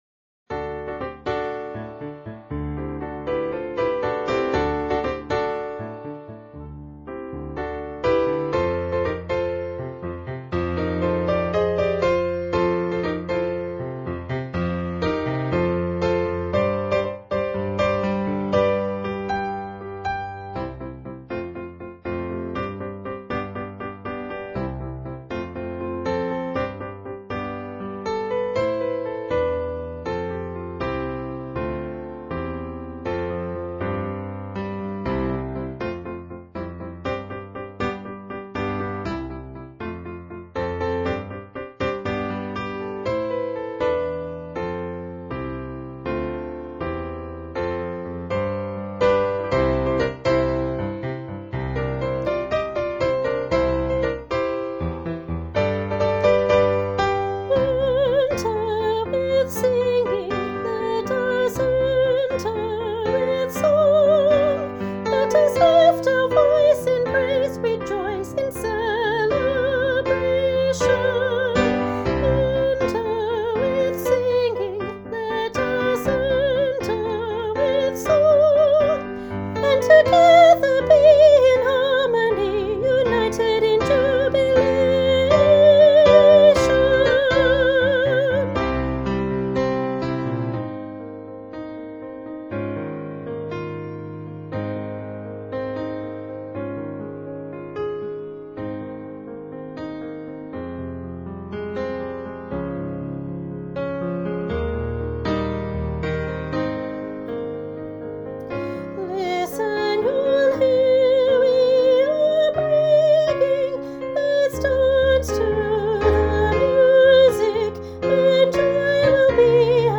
Junior-Choir-Enter-with-Singing-Part-1.mp3